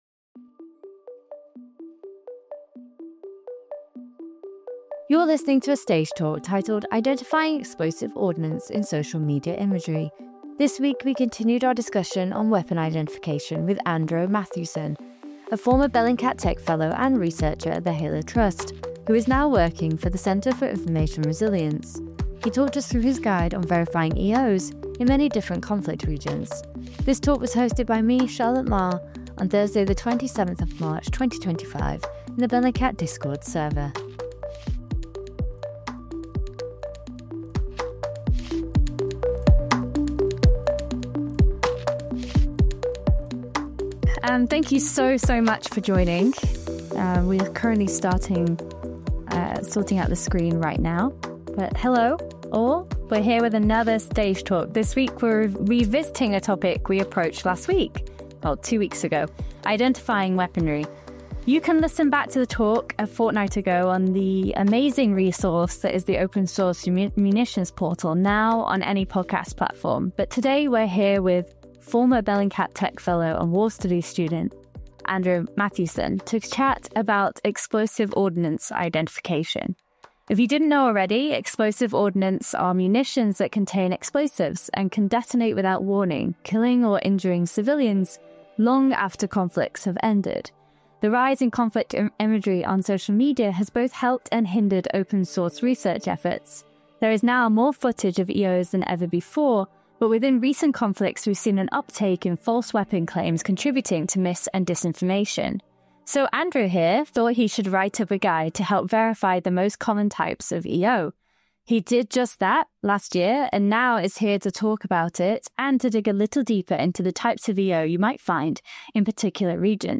It is made up of ordinary people from around the world who dedicate their free time and skills to help out with Bellingcat projects. This is a recording of a Discord Server Stage Talk that took place in the Bellingcat Discord serve